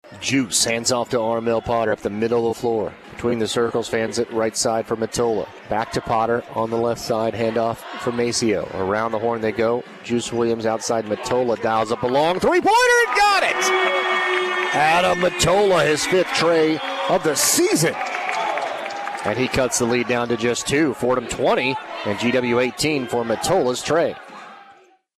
Play-by-Play Highlight